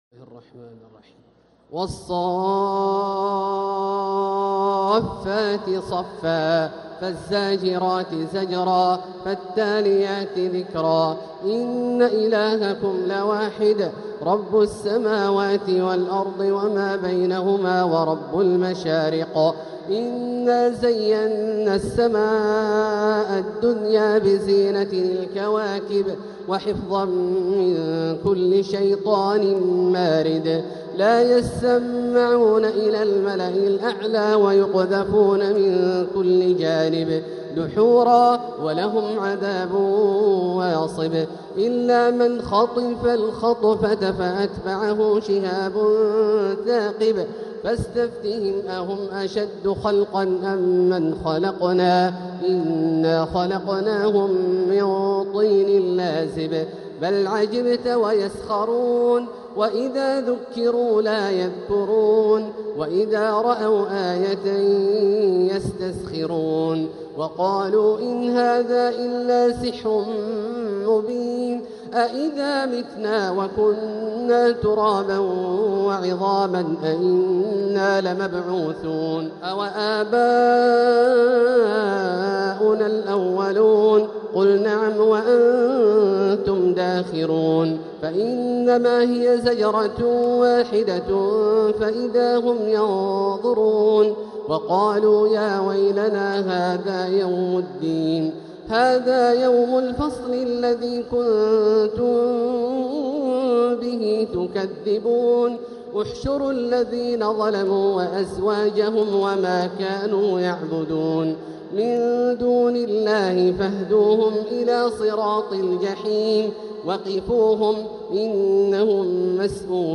سورة الصافات | مصحف تراويح الحرم المكي عام 1446هـ > مصحف تراويح الحرم المكي عام 1446هـ > المصحف - تلاوات الحرمين